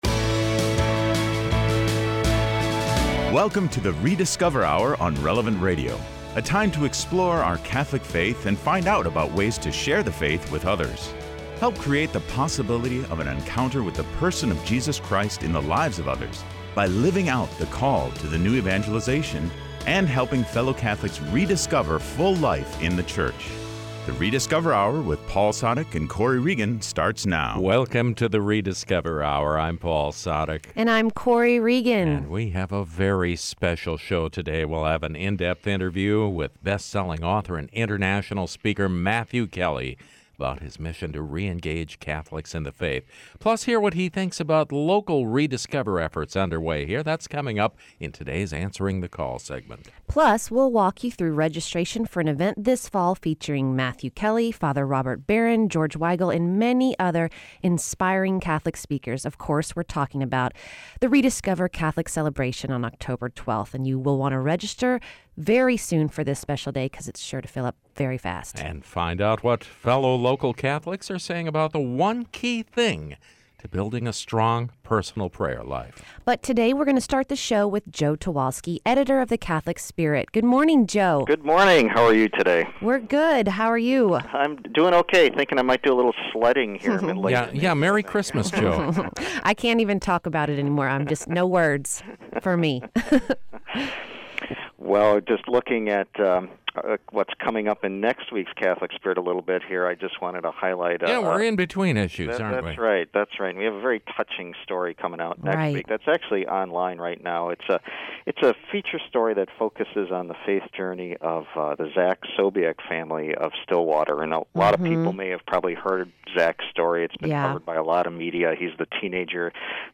On this show, we have an in-depth interview with best-selling author and international speaker Matthew Kelly about his mission to re-engage Catholics in the faith.